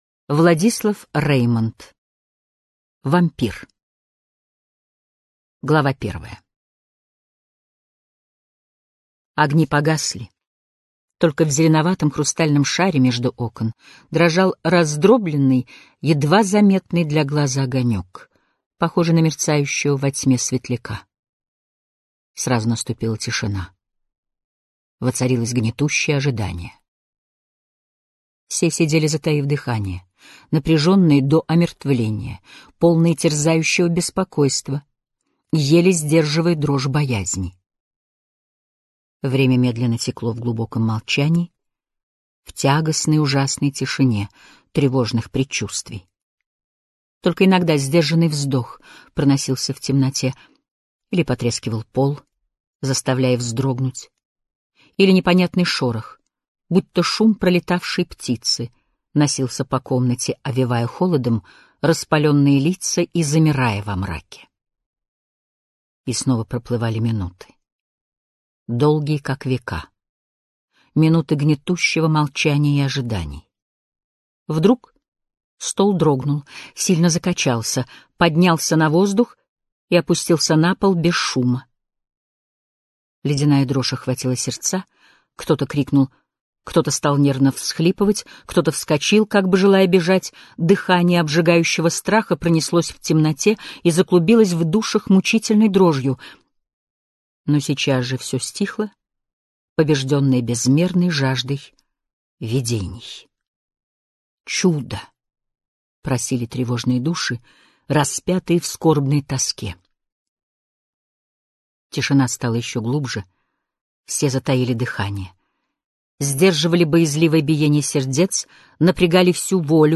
Аудиокнига Вампир | Библиотека аудиокниг